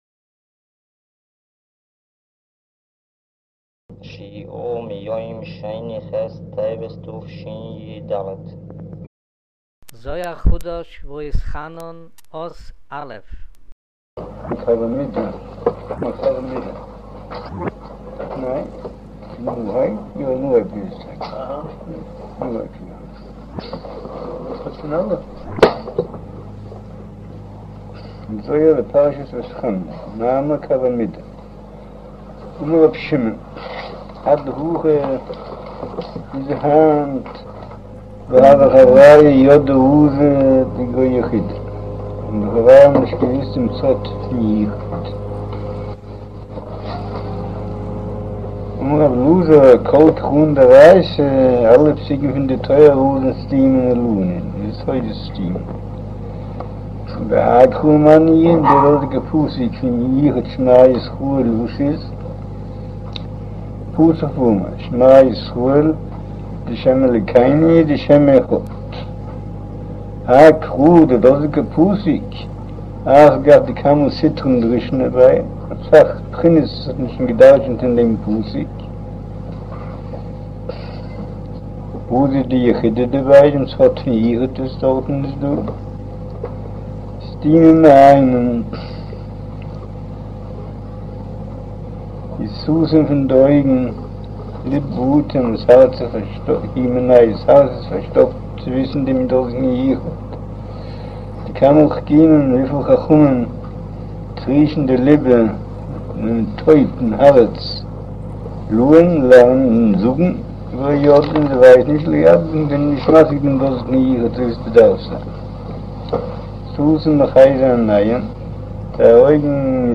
שיעור